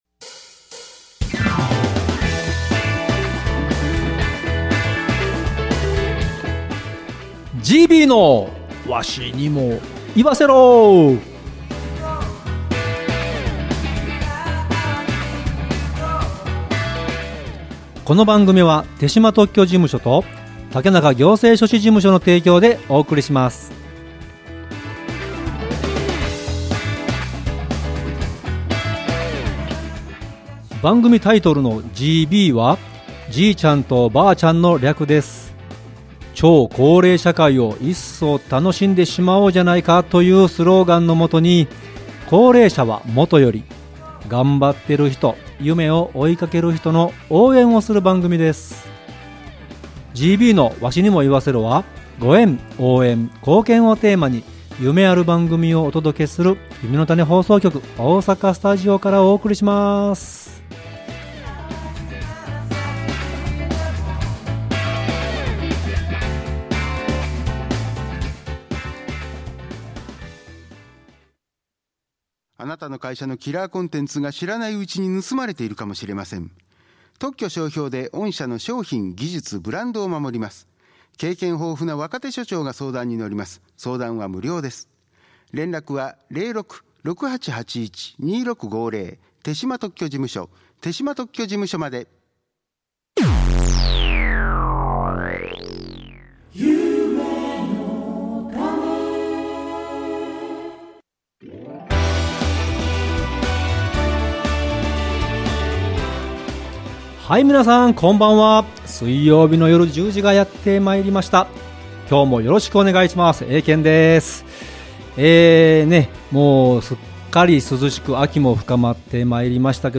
メディアで対談が放映されました